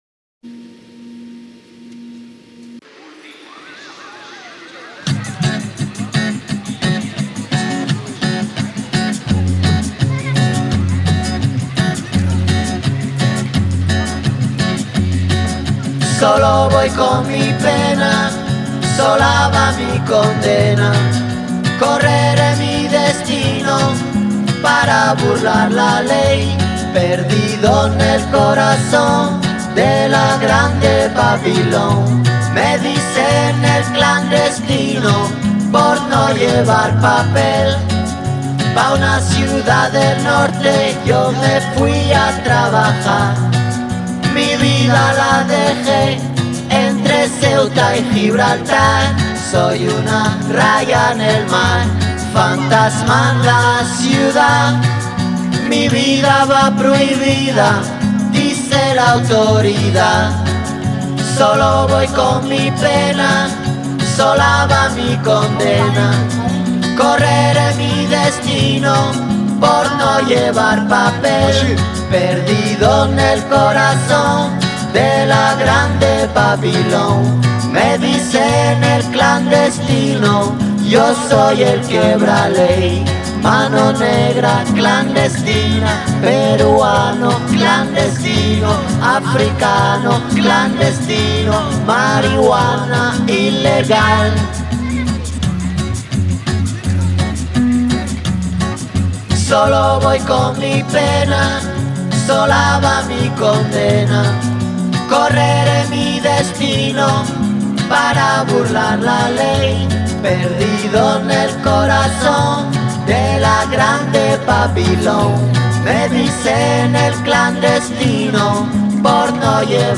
О! - регги! ))))))))))))